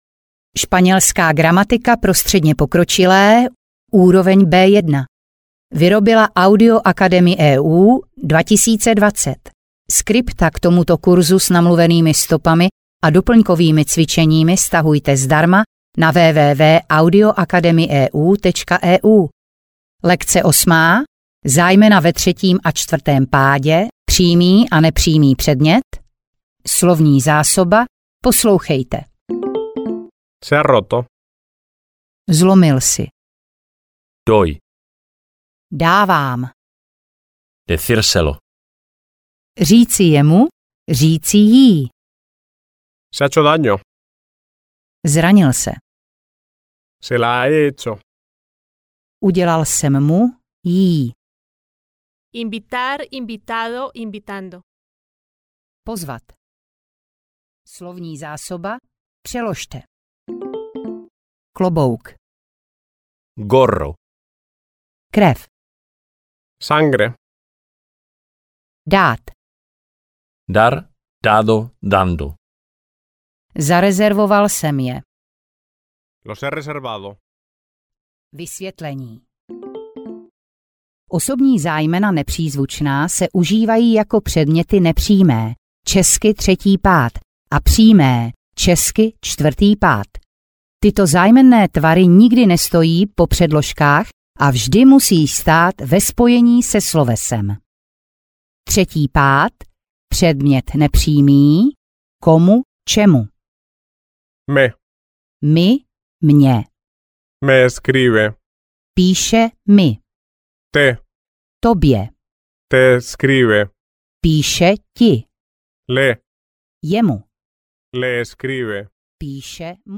Audio knihaŠpanělská gramatika B1
Ukázka z knihy